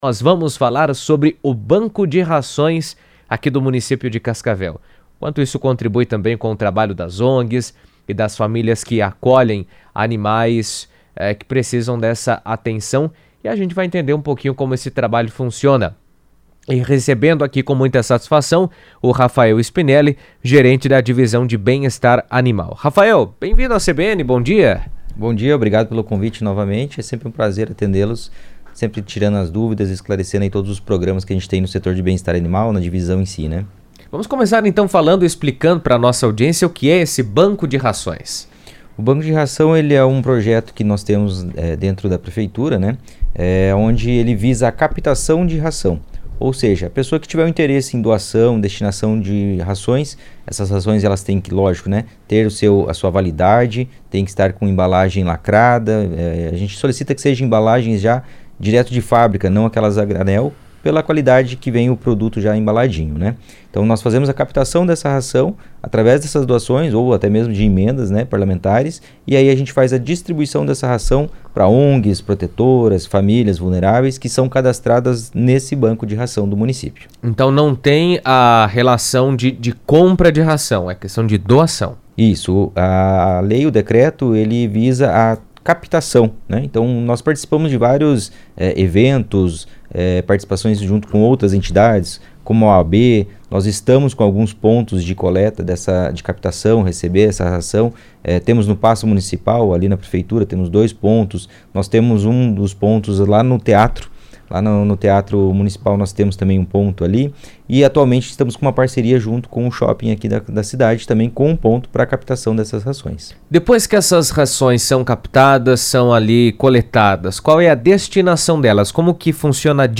O Banco de Ração de Cascavel tem atuado para apoiar ONGs e famílias em situação de vulnerabilidade, oferecendo alimentos para cães e gatos por meio de doações da comunidade e iniciativa privada. Em entrevista à CBN